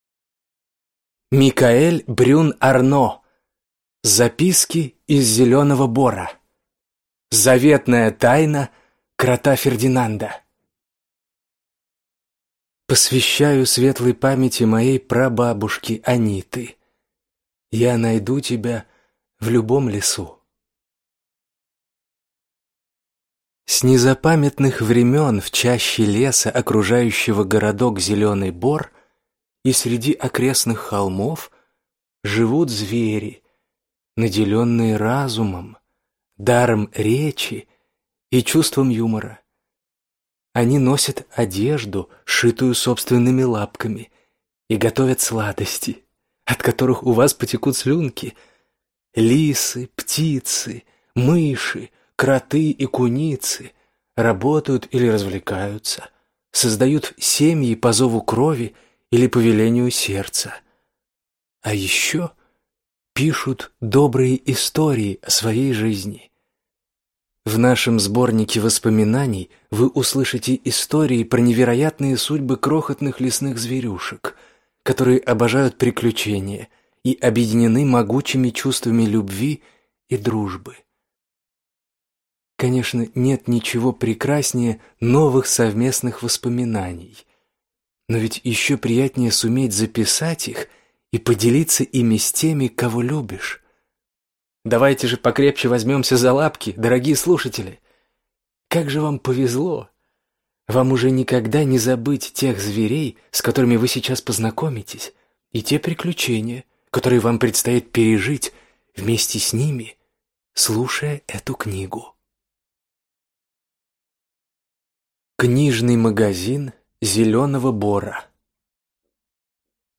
Аудиокнига Записки из Зелёного Бора. Заветная тайна Крота Фердинанда | Библиотека аудиокниг